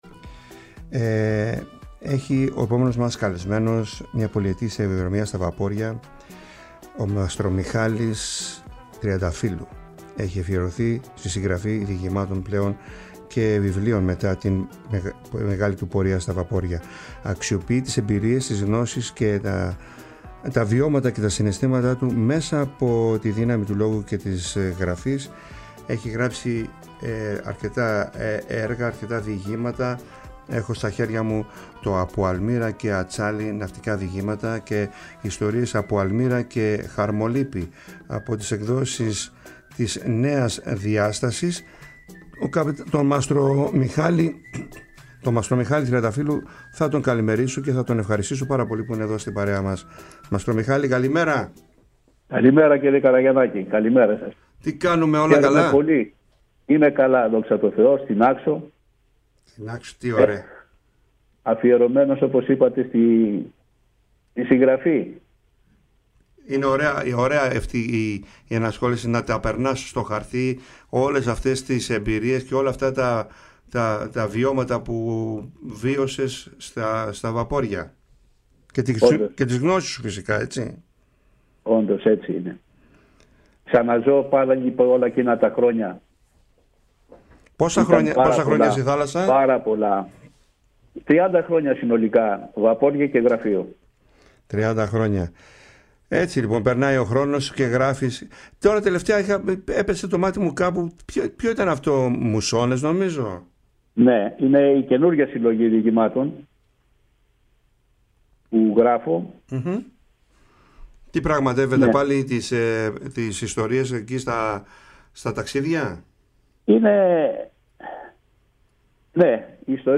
Ο καλεσμένος αφηγήθηκε και διάβασε ιστορίες από το τελευταίο του βιβλίο.
Συνεντεύξεις